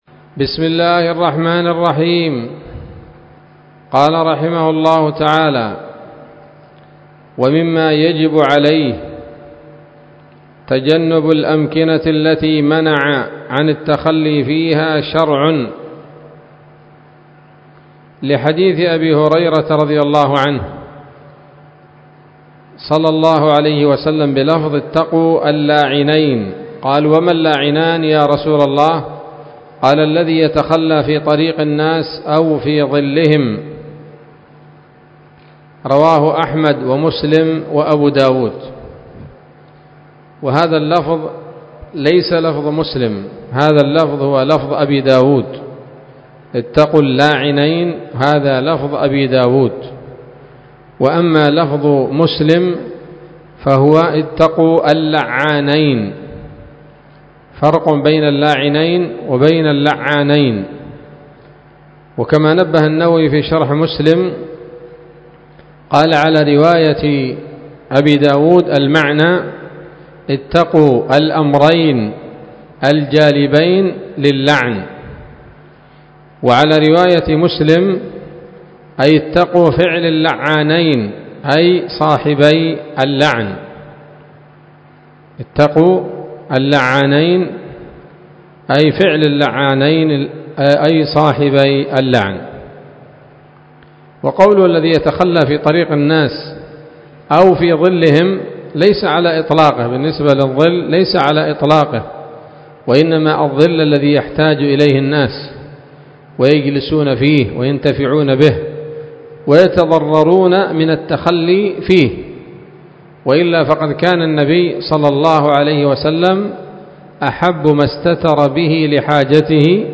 الدرس الثامن من كتاب الطهارة من السموط الذهبية الحاوية للدرر البهية